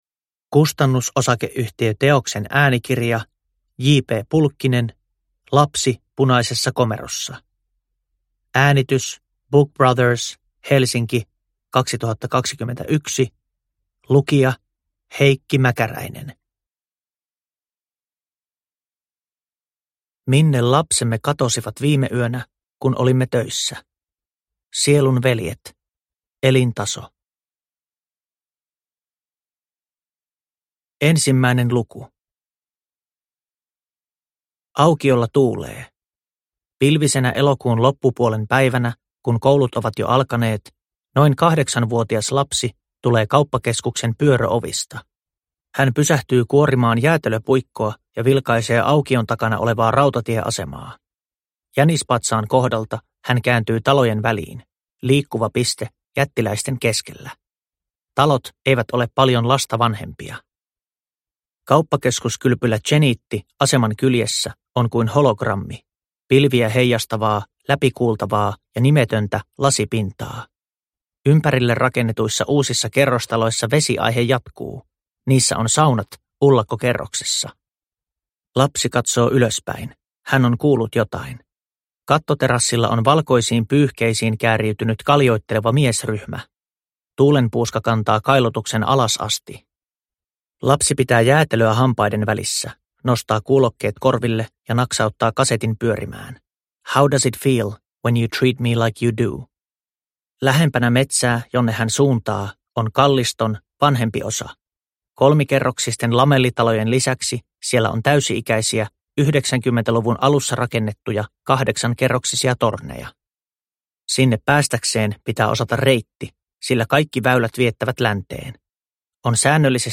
Lapsi punaisessa komerossa – Ljudbok